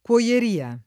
cuoieria [ k U o L er & a ] → coieria